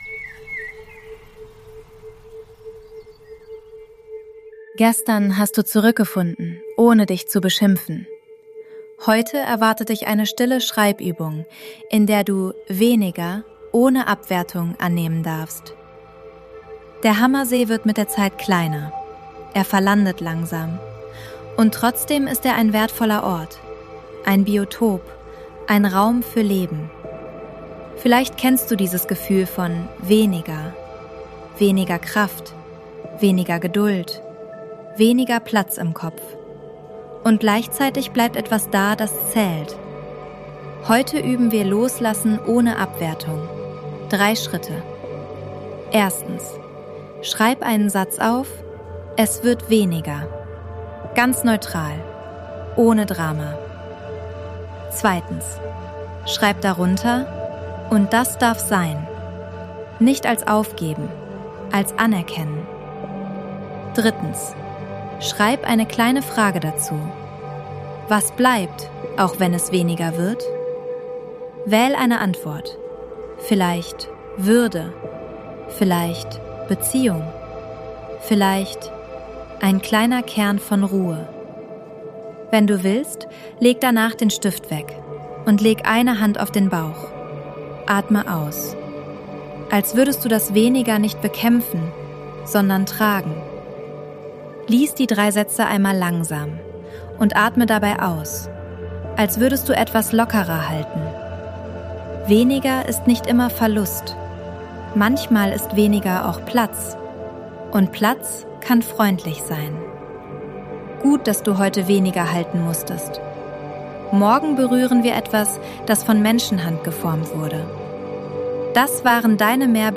Juist Sounds & Mix: ElevenLabs und eigene Atmos